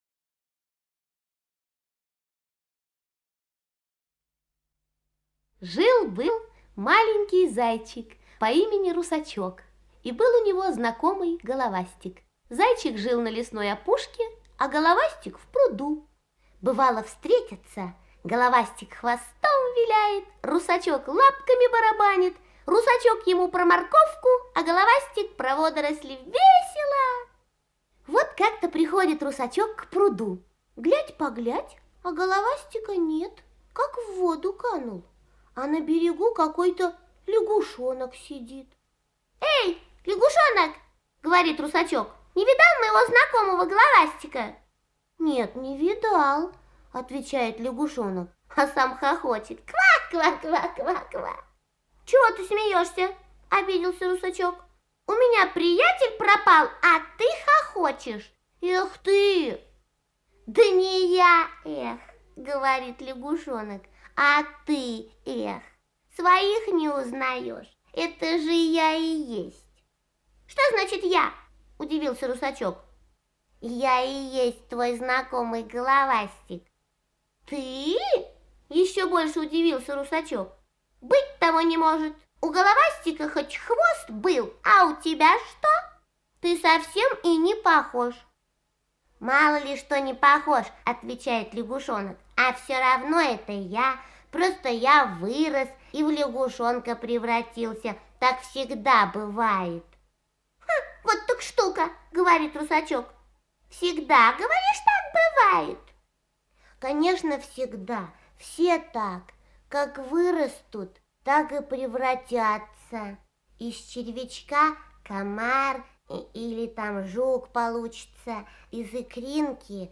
Русачок - аудиосказка Заходера Б. Сказка про зайчика по имени Русачок и его друга Головастика, который превратился в Лягушонка.